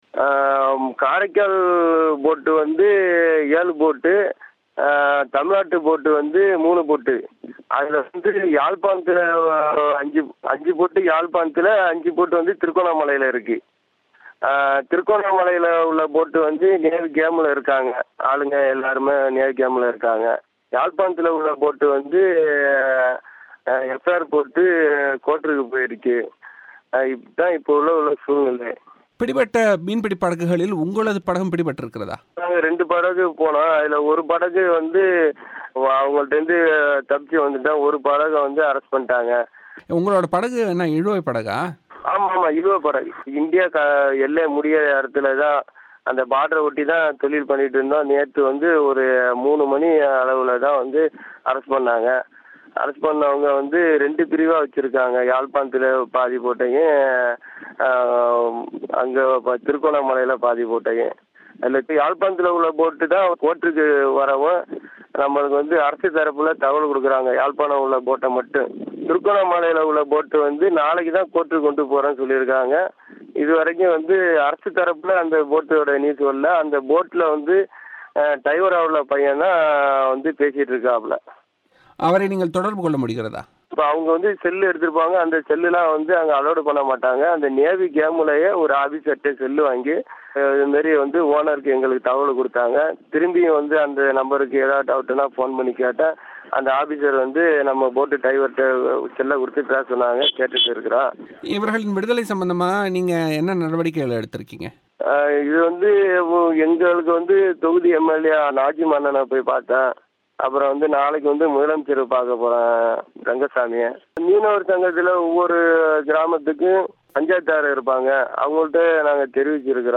பேட்டி